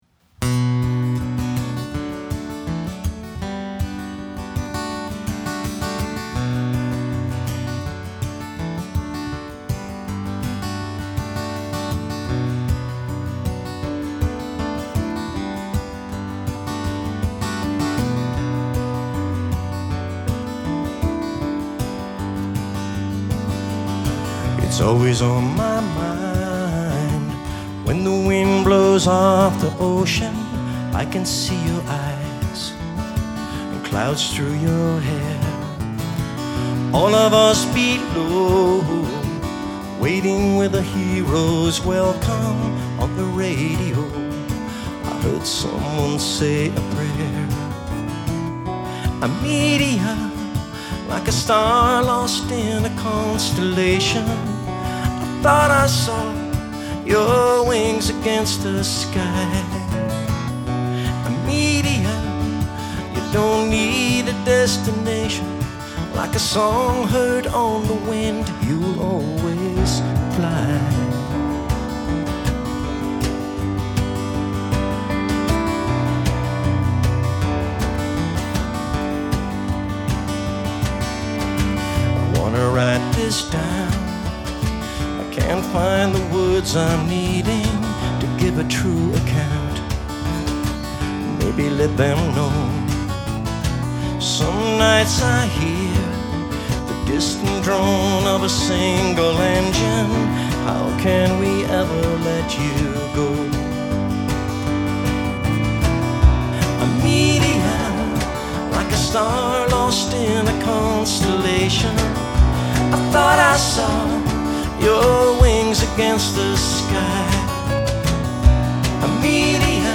This was recorded live in Halifax, NS.